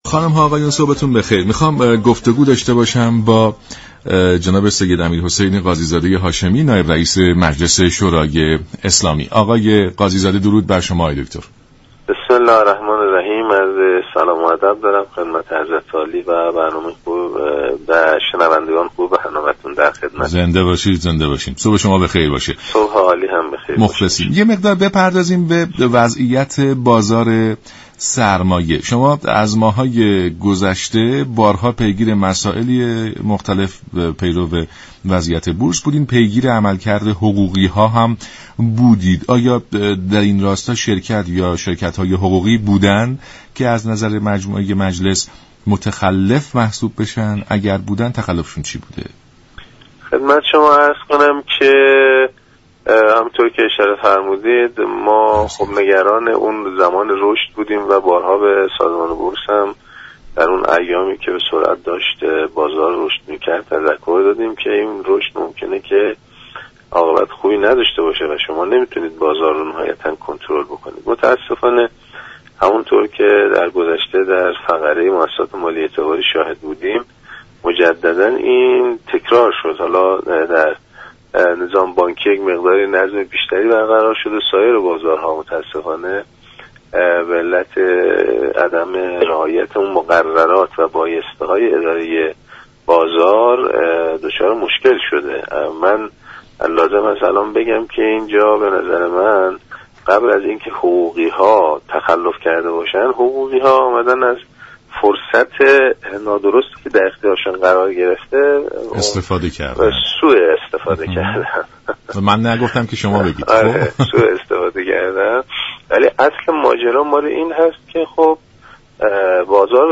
به گزارش شبكه رادیویی ایران، سید امیر حسین قاضی زاده هاشمی نماینده مجلس شورای اسلامی در برنامه سلام صبح بخیر رادیو ایران درباره وضعیت بازار سرمایه ایران گفت: در بحث بورس، بازار ایران به علت عدم رعایت قوانین و بایسته های اداره بازار، امروزدچار مشكل شده است.